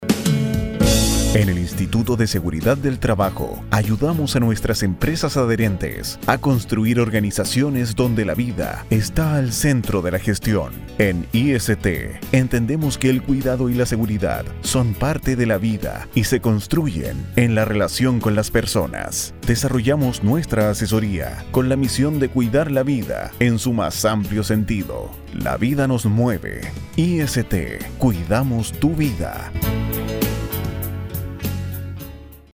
Voz en Off
IST  - Campaña radial (2014)